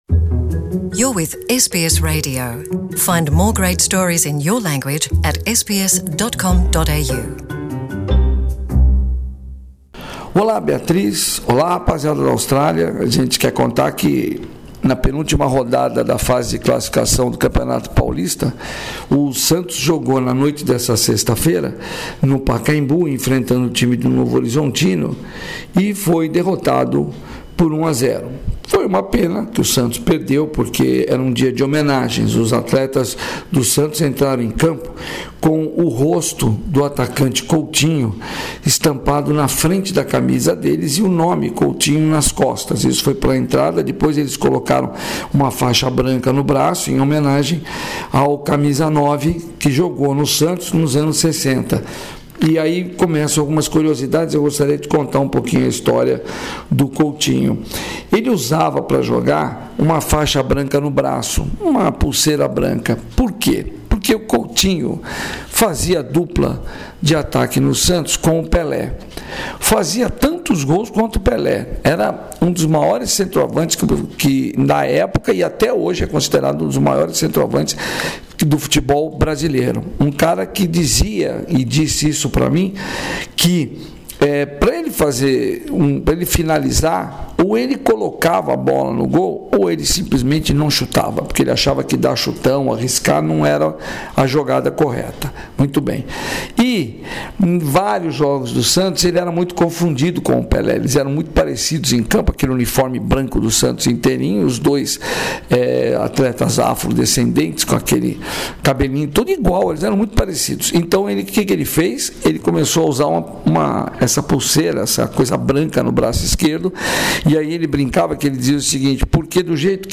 Ainda neste boletim esportivo, Grêmio e Atlético Mineiro entraram em apuros na fase de grupos da Copa Libertadores da América. E o técnico Tite está de olho no atacante Dudu do Palmeiras para as próximas convocações.